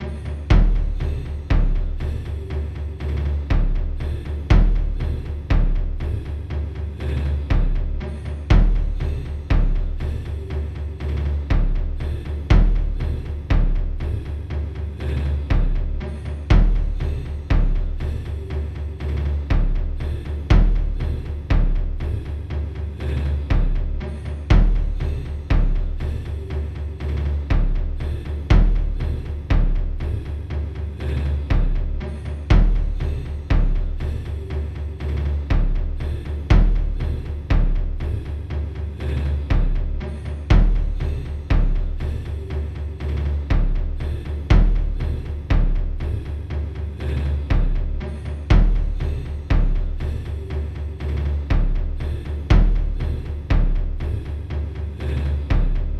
响尾蛇介绍循环
用Korg wavestation vsti制作。